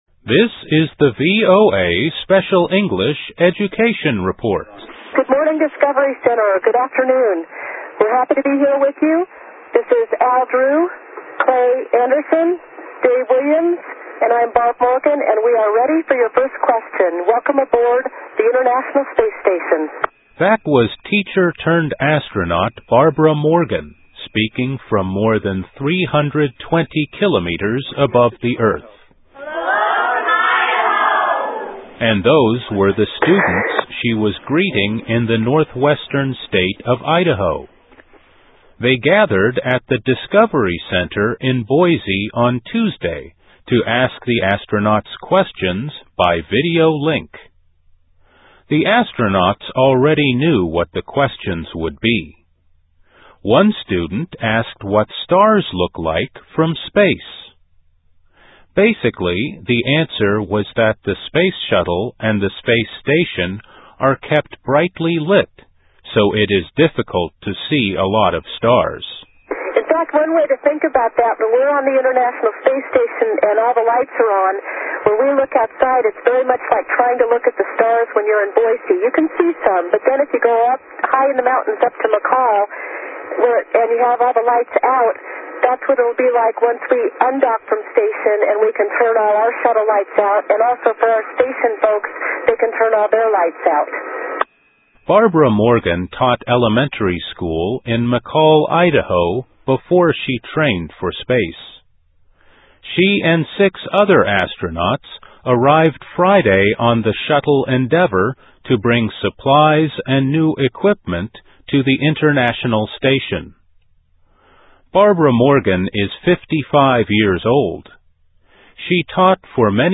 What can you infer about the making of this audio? This was broadcast on August 15, 2007.